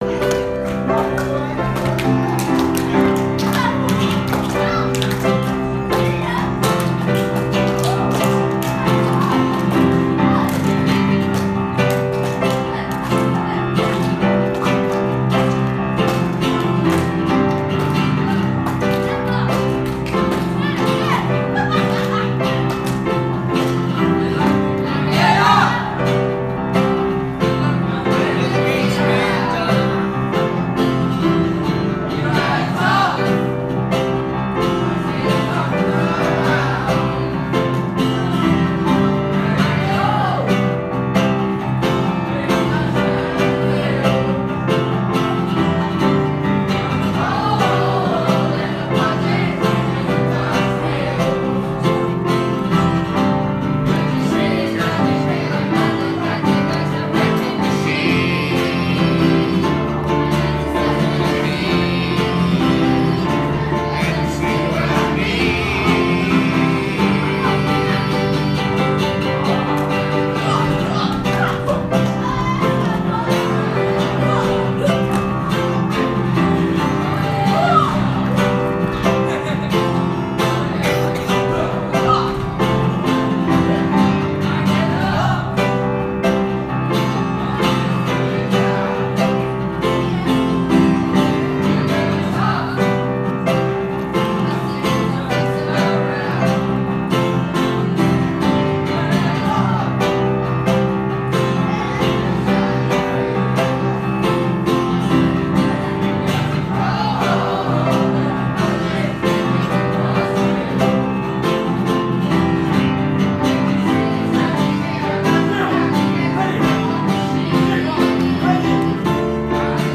Lansdowne School Choir